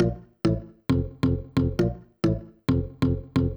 GAR Organ Riff Bb-A-F.wav